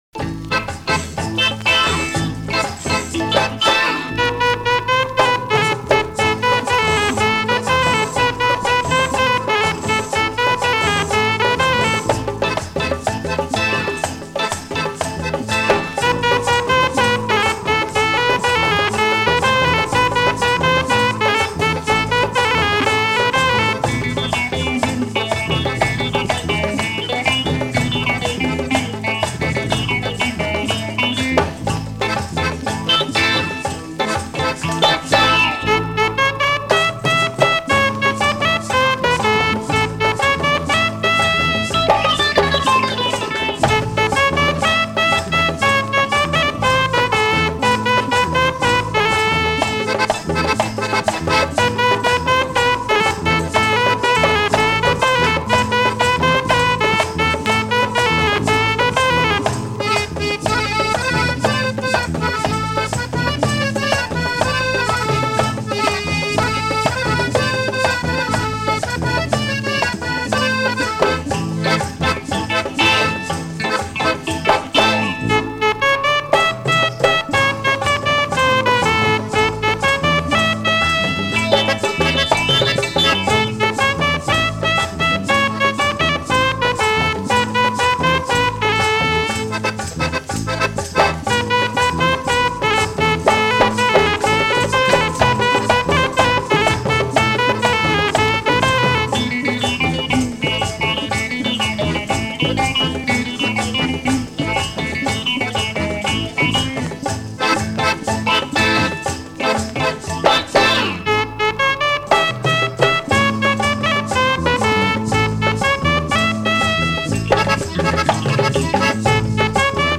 instrumental Bollywood covers
clavioline